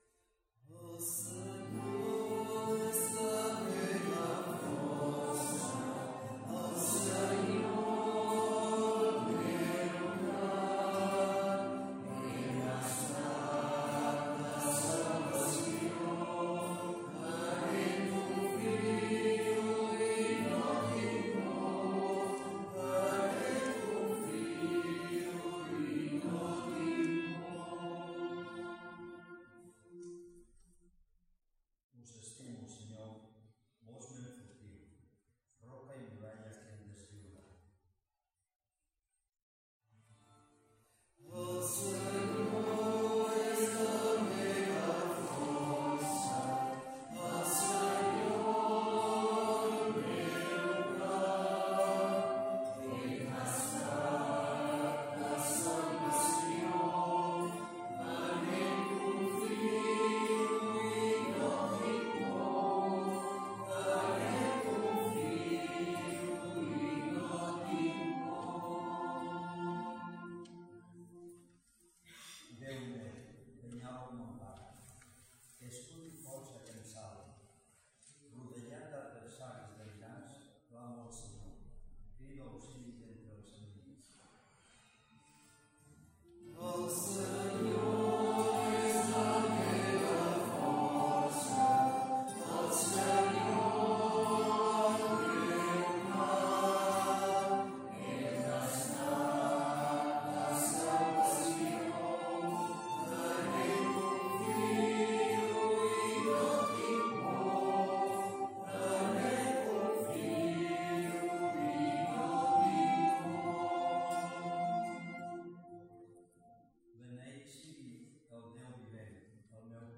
Pregària de Taizé a Mataró... des de febrer de 2001
Església de Santa Anna - Diumenge 29 d'octubre de 2023
Vàrem cantar...